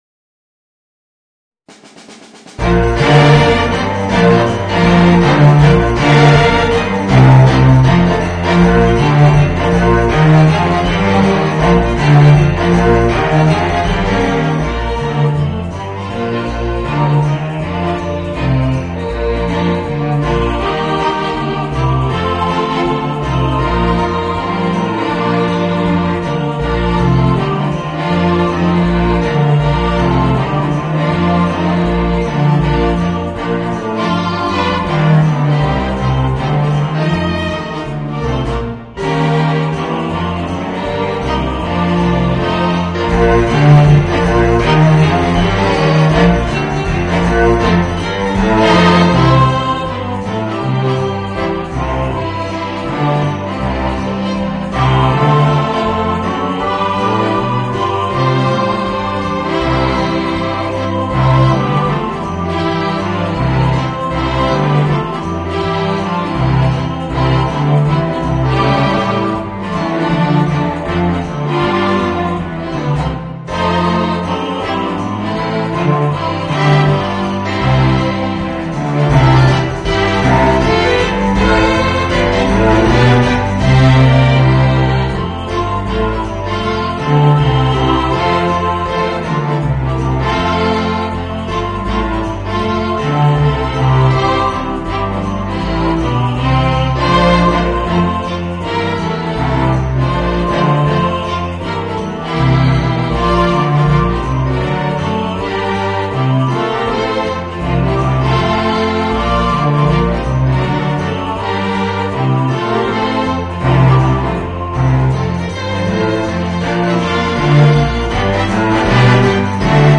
Voicing: String Quintet and Chorus